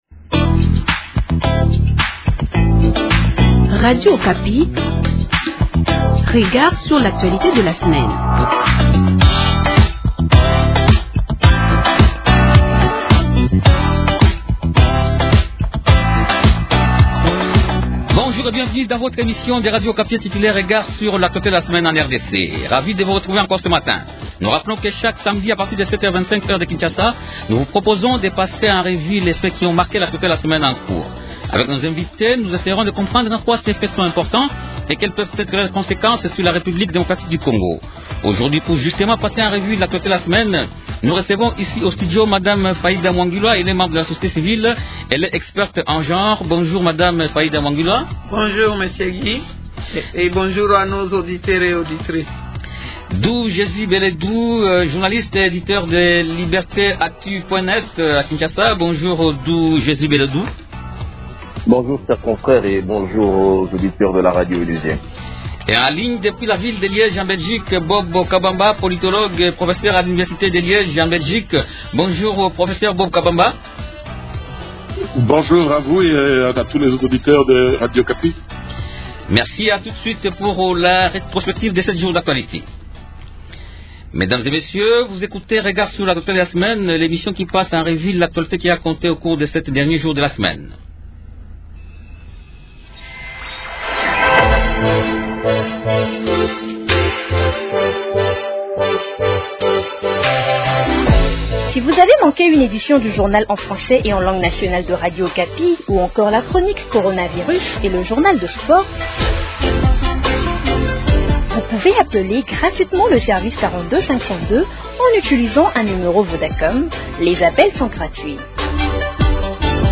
-Et en ligne depuis la ville de Liège en Belgique